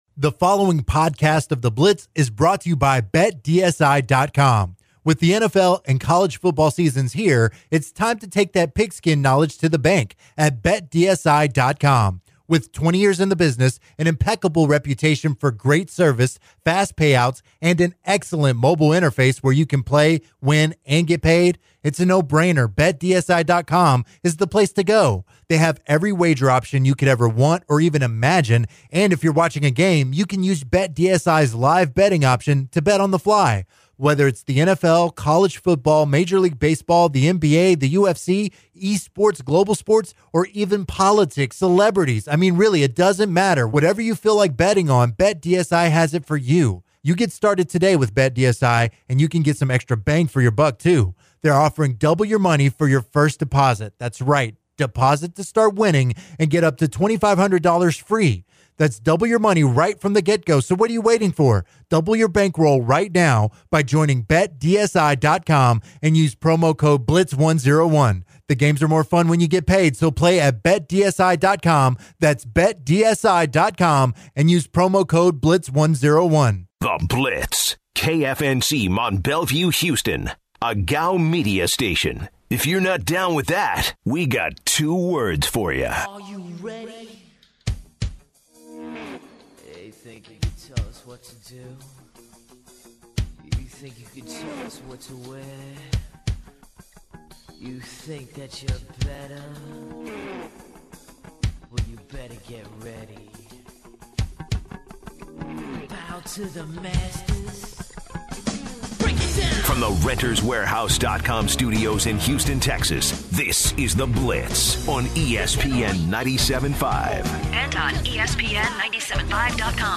taking calls from fans debating the content of their show. The guys switch over to the requested sports talk, discussing the upcoming Texans vs. Colts match-up and analyzing the remaining NFL teams going into the playoffs. They further break down their predictions for this weekend and the Texans’ hopes. The men wrap up the hour with their gems of the day and share some first date stories.